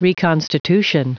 Prononciation du mot reconstitution en anglais (fichier audio)